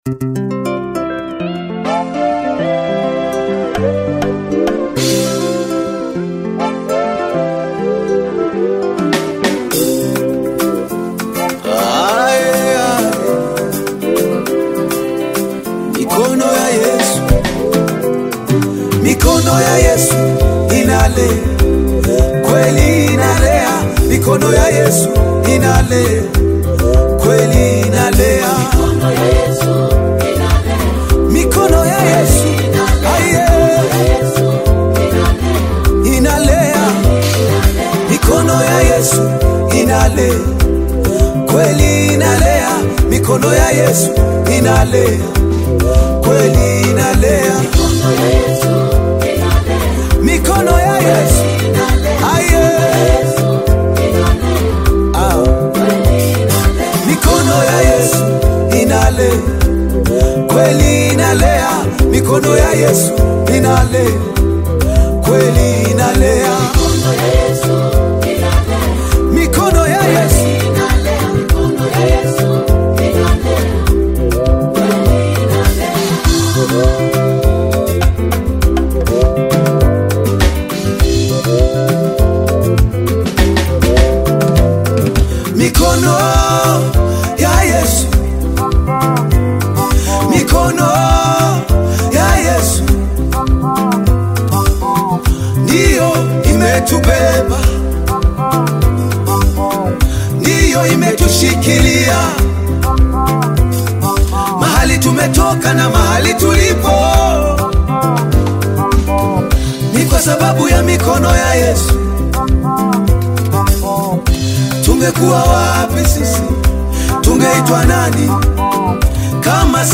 GOSPEL
A truly uplifting and anointed worship piece.